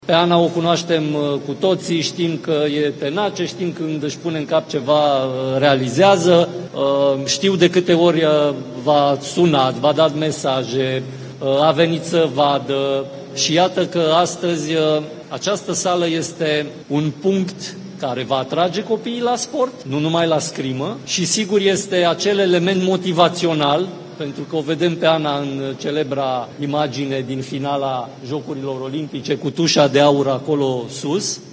Președintele Comitetului Olimpic și Sportiv Român, Mihai Covaliu: Copiii ar putea fi atrași să facă sport, nu numai scrimă